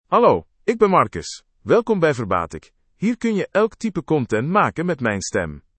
Marcus — Male Dutch (Belgium) AI Voice | TTS, Voice Cloning & Video | Verbatik AI
MaleDutch (Belgium)
MarcusMale Dutch AI voice
Marcus is a male AI voice for Dutch (Belgium).
Voice sample
Listen to Marcus's male Dutch voice.
Marcus delivers clear pronunciation with authentic Belgium Dutch intonation, making your content sound professionally produced.